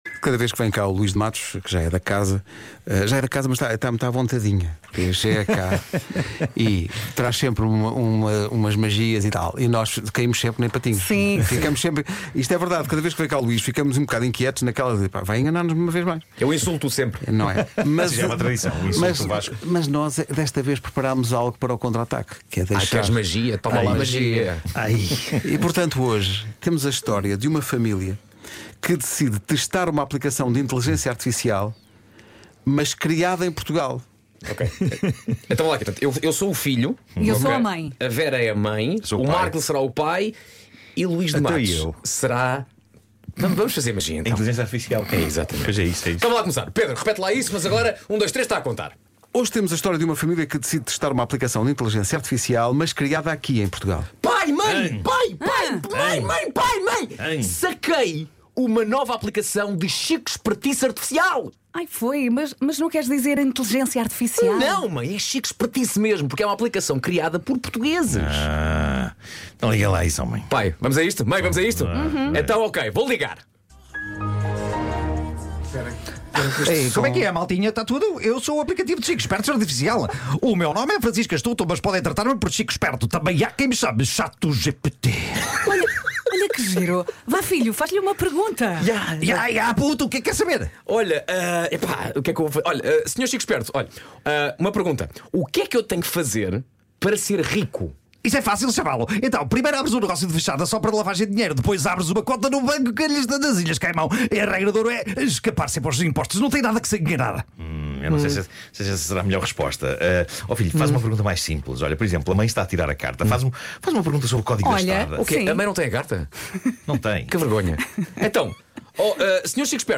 Radionovela das Manhãs da Comercial
Com a participação especial do Luís de Matos.